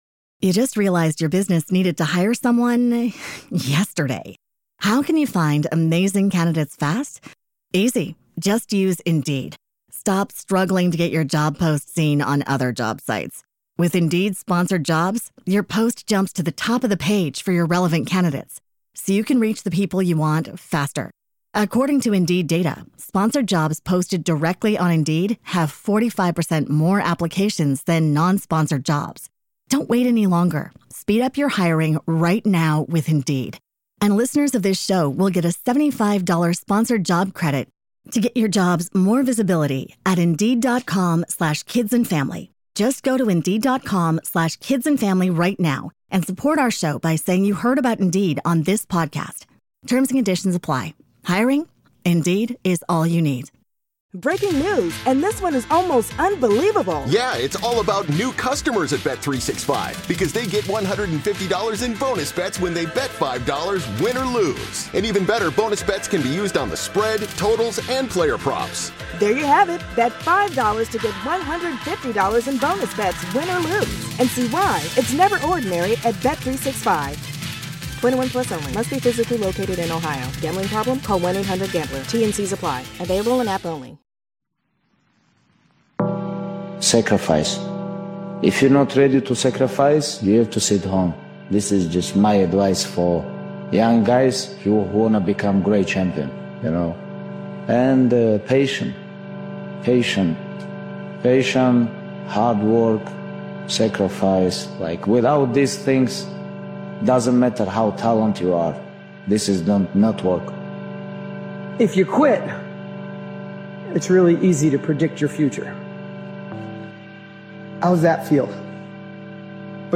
This high-impact collection brings together some of the most inspiring voices and hard-hitting messages of the year, crafted to push you into action, shift your mindset, and help you operate like the top 1%.